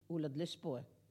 Collectif-Patois (atlas linguistique n°52)
Catégorie Locution